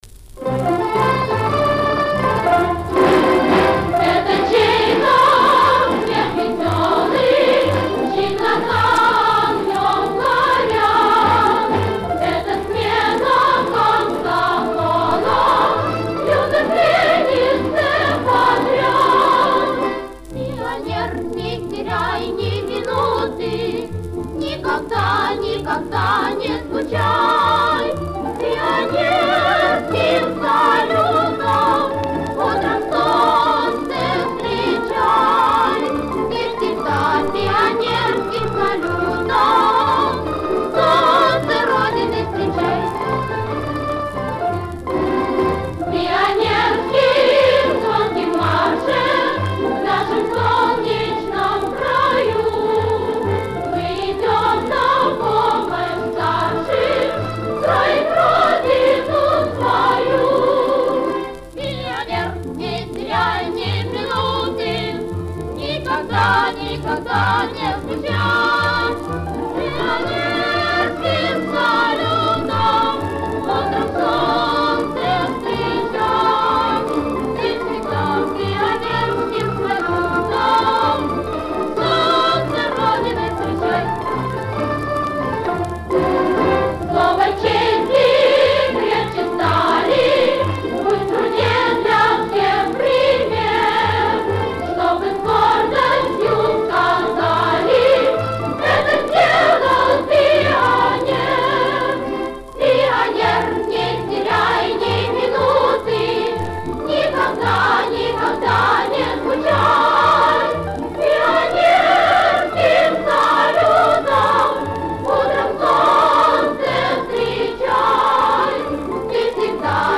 Патриотическая детская песенка.